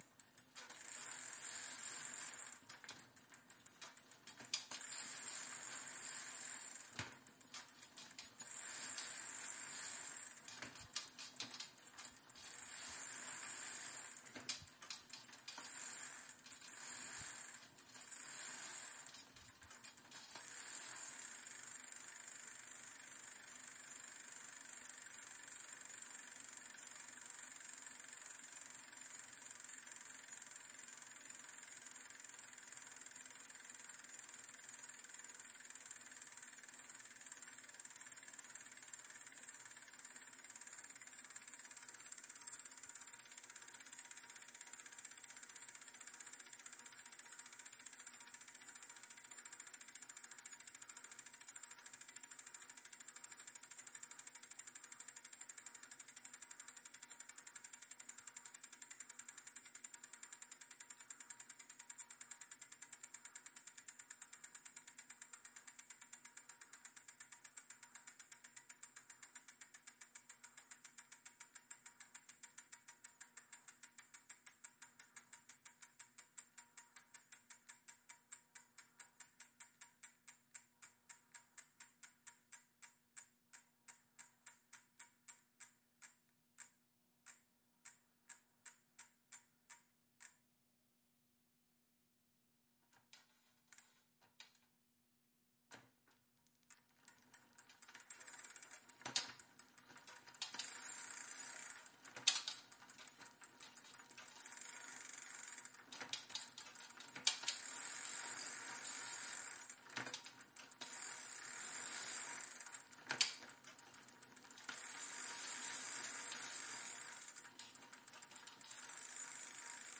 OWI SFX声音 " 汤米枪玩具
描述：来自自行车的自由旋转链条声音。
标签： 周期 OWI 齿轮 自行车 链条 自行车 车轮
声道立体声